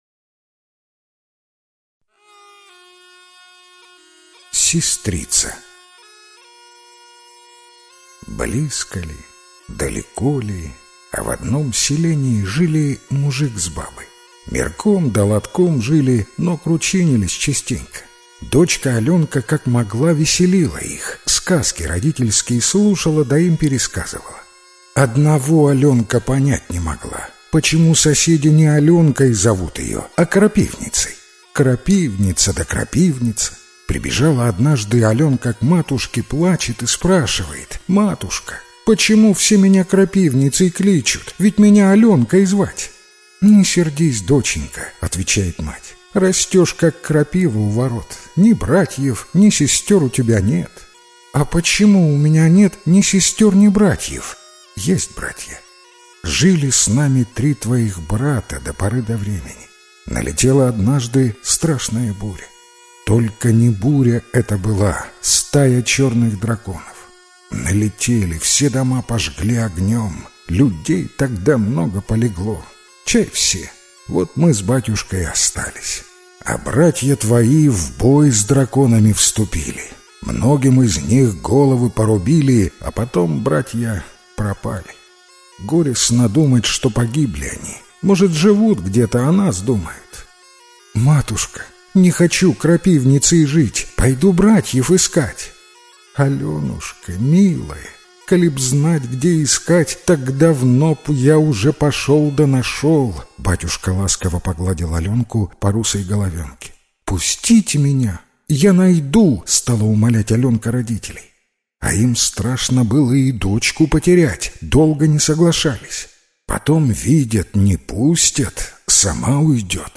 Сестрица - белорусская аудиосказка - слушать онлайн